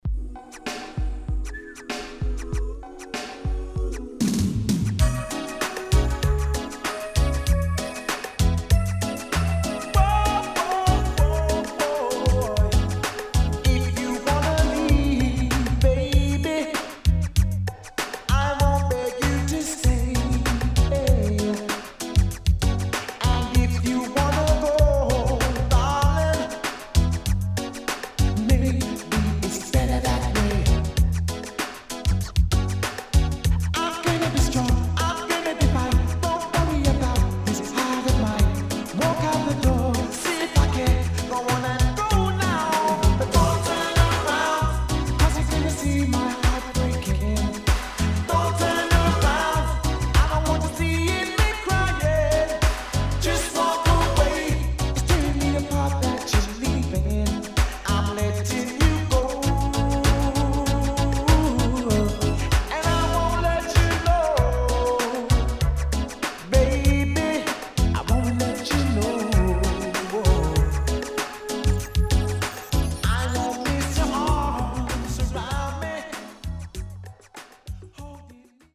HOME > LP [DANCEHALL]